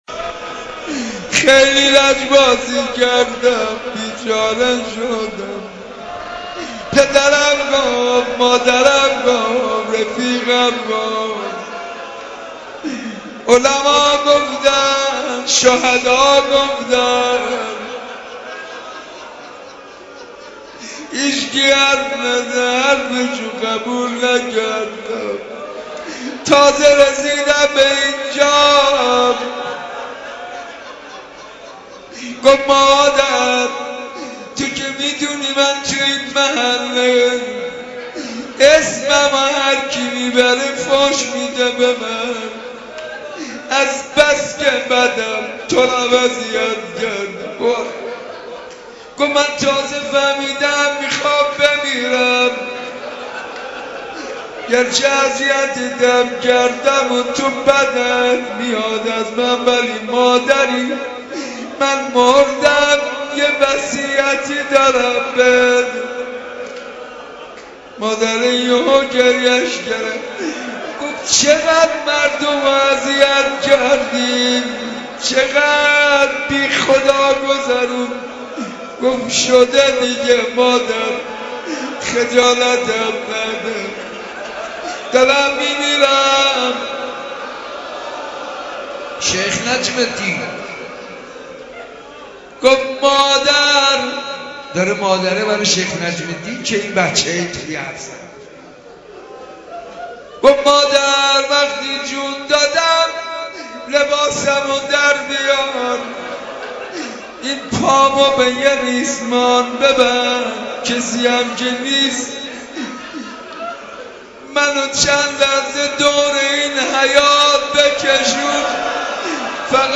مناجات